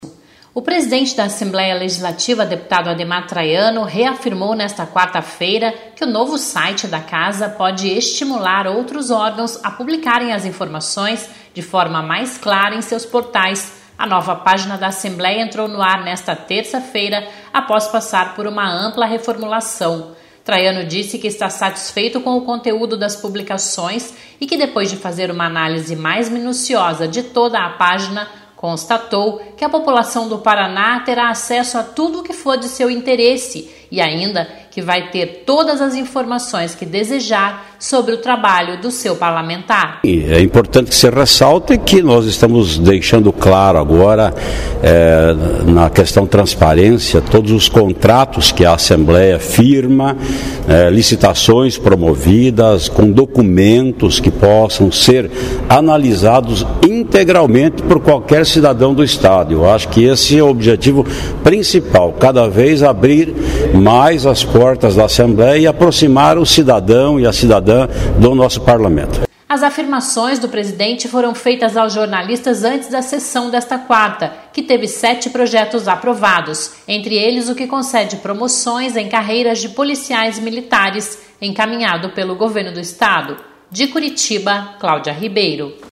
As afirmações do presidente foram feitas aos jornalistas  antes da sessão desta quarta, que teve sete projetos aprovados, entre eles, o que concede promoções em  carreiras  de policias militares, encaminhado pelo Governo do Estado.